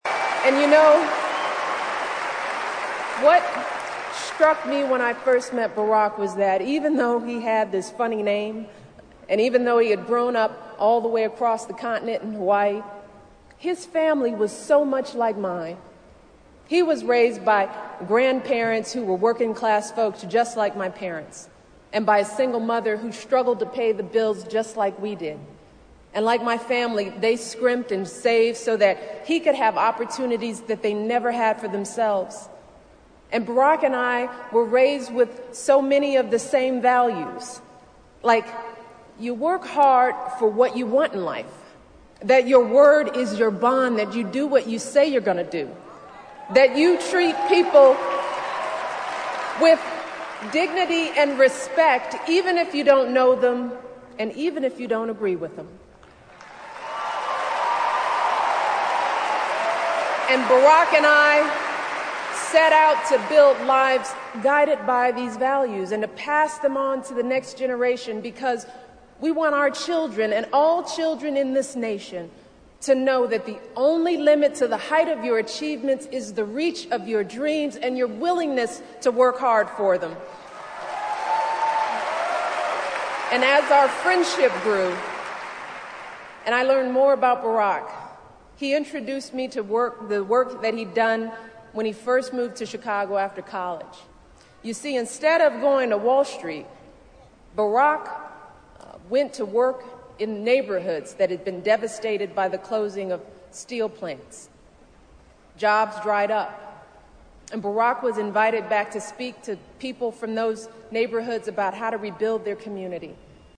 名人励志英语演讲 第90期:让我们选举巴拉克·奥巴马为美利坚合众国总统(3) 听力文件下载—在线英语听力室